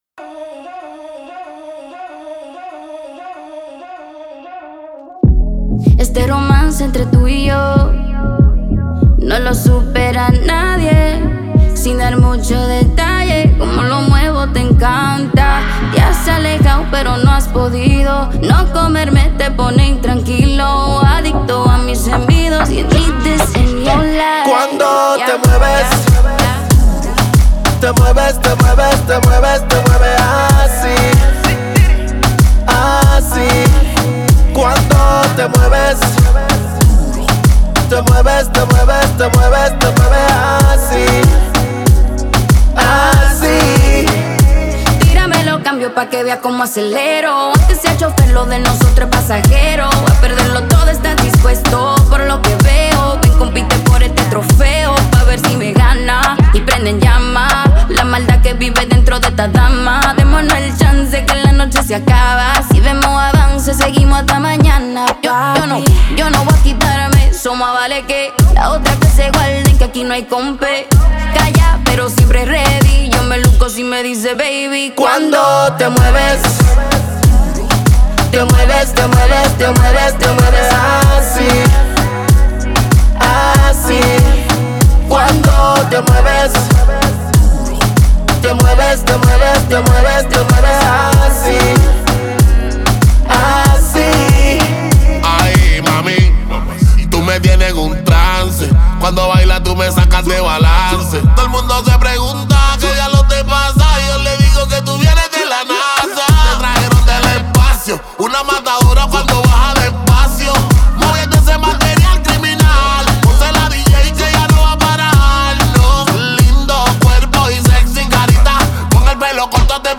зажигательная реггетон-композиция